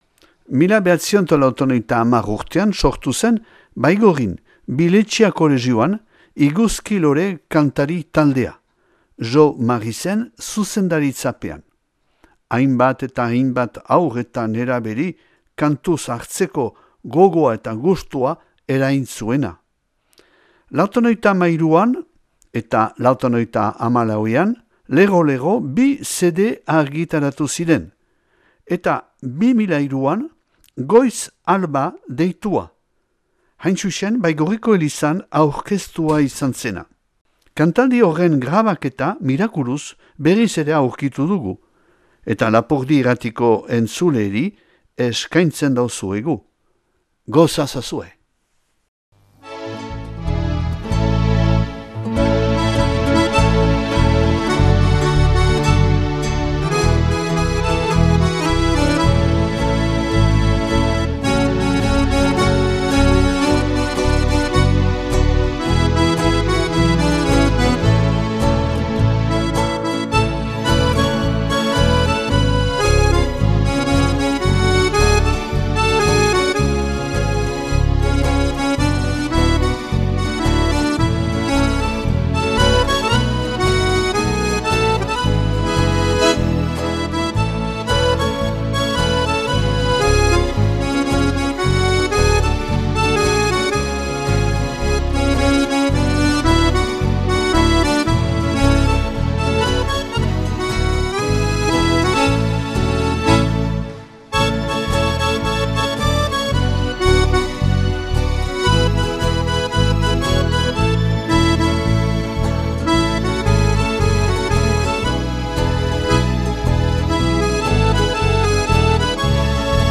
Iguzki Lore kantari taldearen kantaldia (2003 - Baigorriko Elizan)